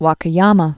(wäkə-yämə)